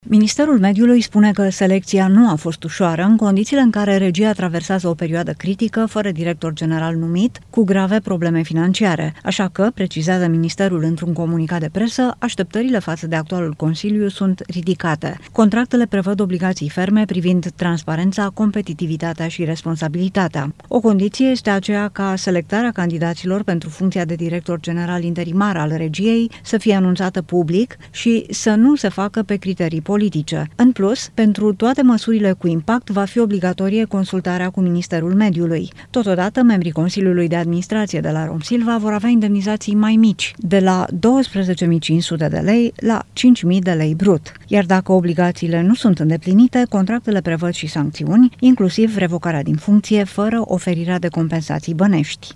Ministra Mediului, Diana Buzoianu: „Vom urmări îndeaproape activitatea acestui Consiliu – fiecare decizie, fiecare numire”